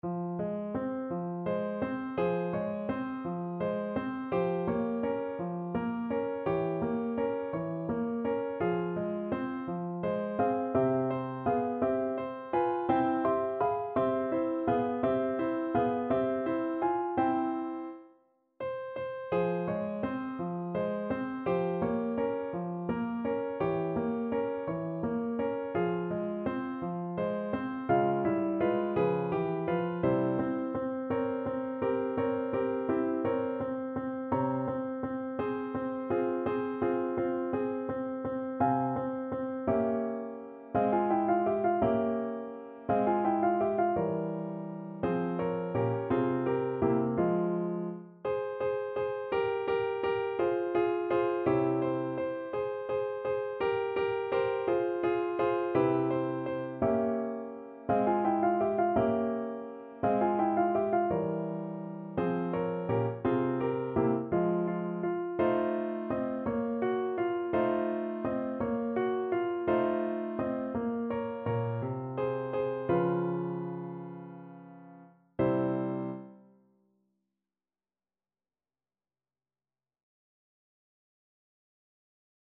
. = 56 Andante
6/8 (View more 6/8 Music)
Classical (View more Classical Clarinet Music)